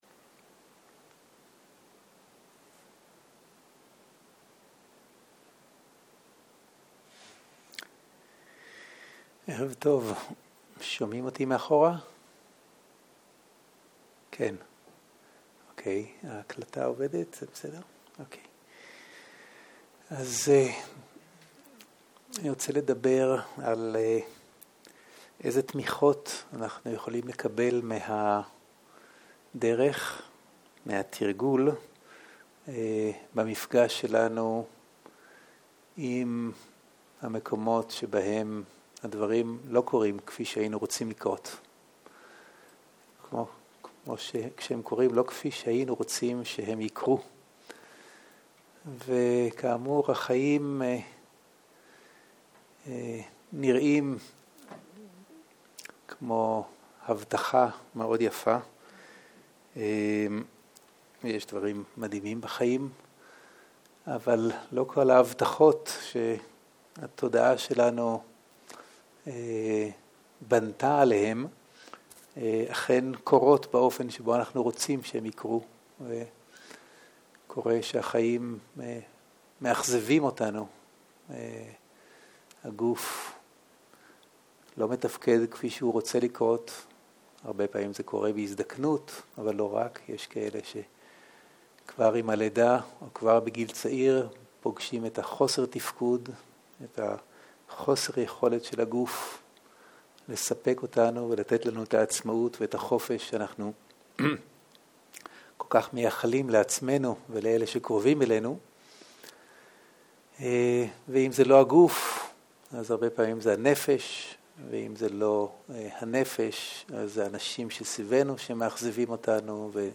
סוג ההקלטה: שיחות דהרמה